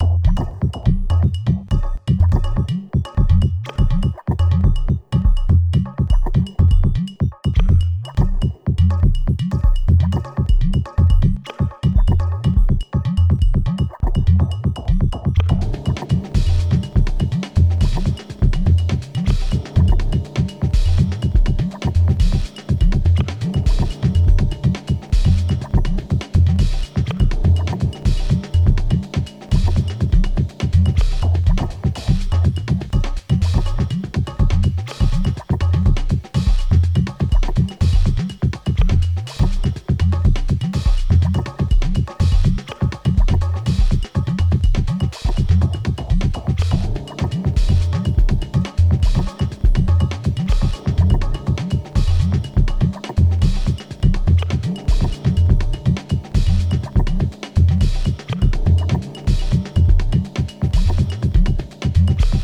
La techniques des rythmes euclidiens est magique